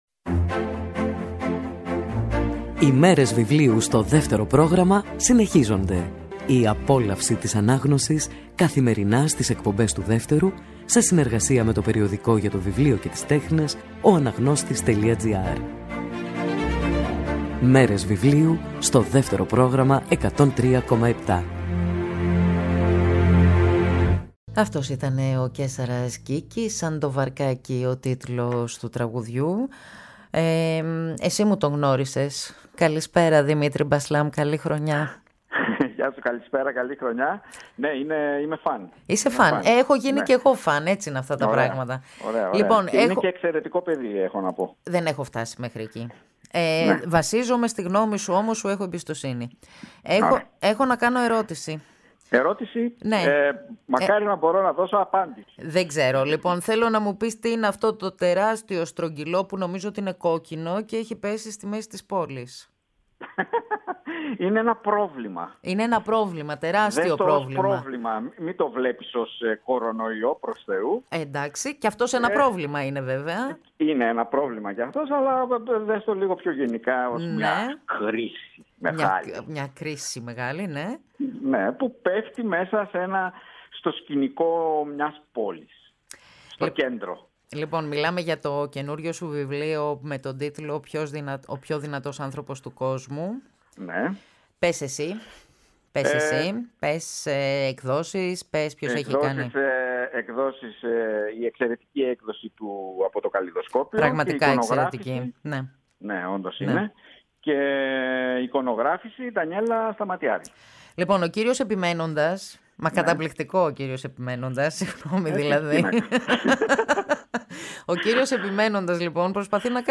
συζήτηση